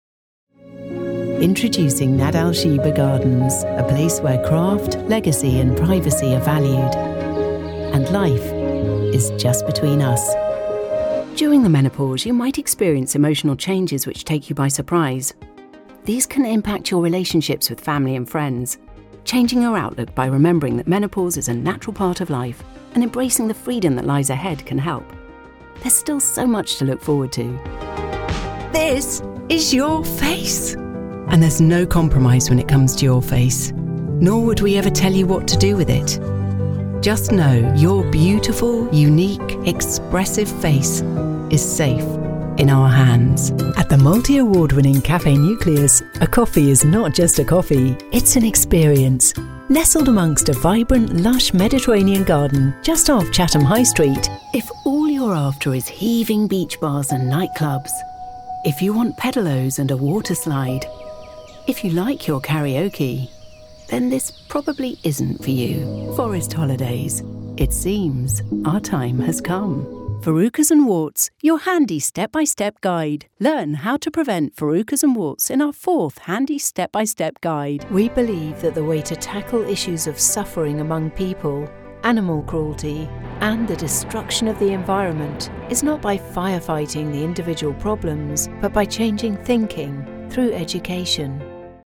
British English Female Voice Over Artist
Assured, Authoritative, Confident, Conversational, Corporate, Deep, Engaging, Friendly, Funny, Gravitas, Natural, Posh, Reassuring, Smooth, Upbeat, Versatile, Warm
Microphone: Neumann TLM 103
Audio equipment: Focusrite clarett 2 PRE, Mac, fully sound-proofed home studio